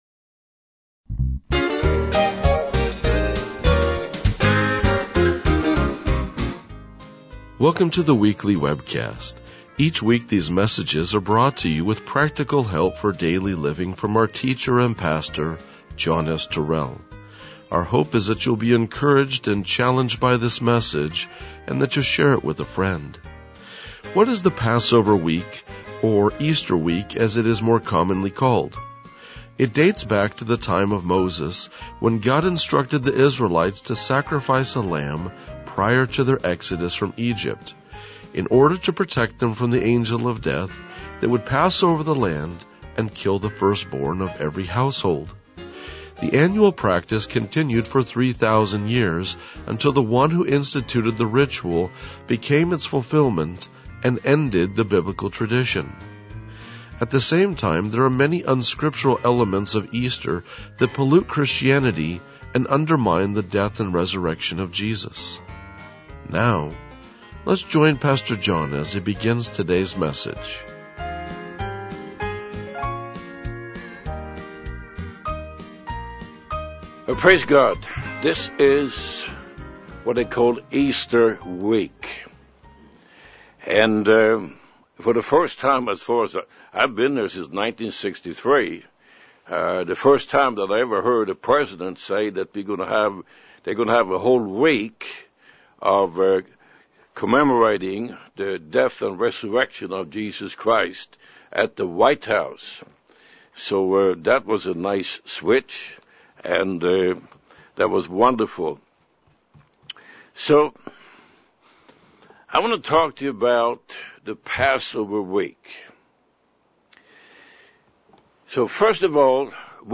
RLJ-2010-Sermon.mp3